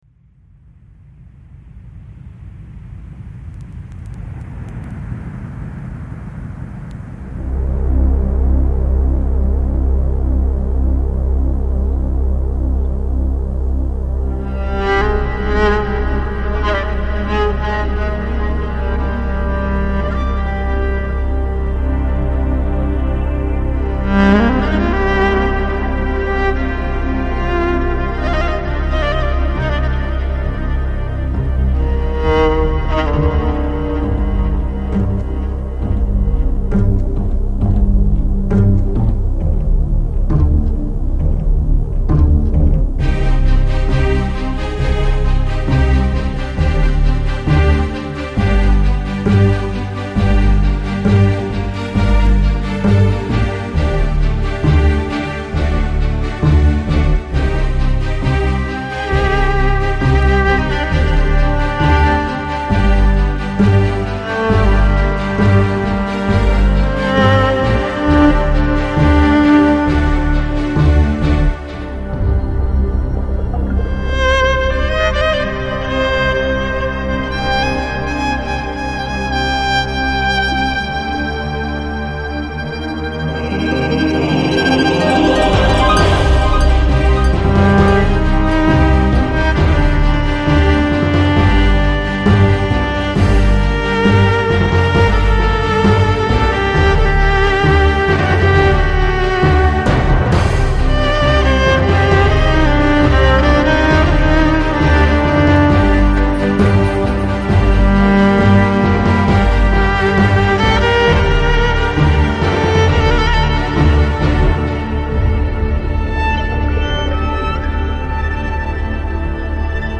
Жанр: Electro, Techno